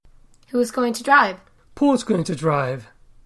One interesting fact about the deaccentuation of repeated words is that it applies to all the speakers involved at any given moment in the exchange. This means that, if a word has already been uttered, the next speakers acknowledge that that lexical item is now considered old information by deaccenting it whenever they have to repeat it.